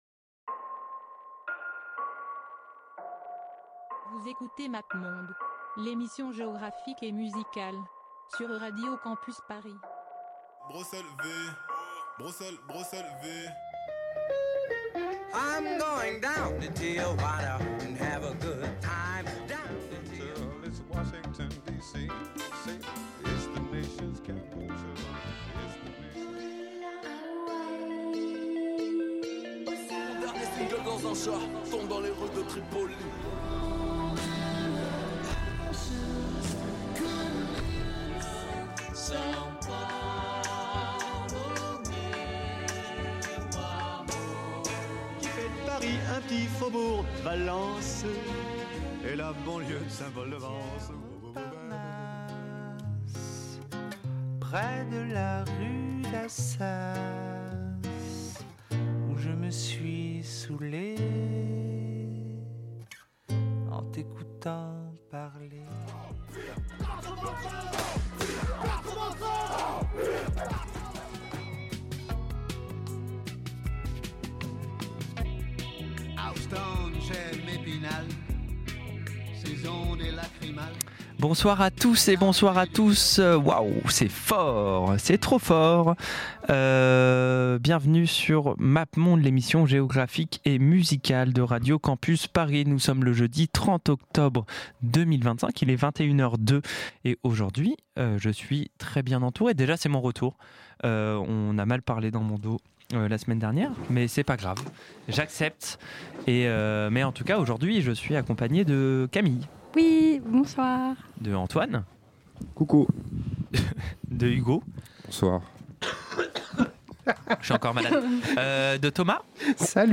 Une émission extrêmement chaotique où, faute d'information sur les artistes, on vous raconte des histoires de peuples déportés, d'ethnies en conflit et d'attentats en Tchétchénie. Une tracklistavec beaucoup d'accordéon, de danses circassiennes, de polyphonies paganes d'Alanie, de la musique pop inspirée de musiques traditionnelles, de cold wave des morceaux issus de compilations louches et du métal d'Ossétie du Nord.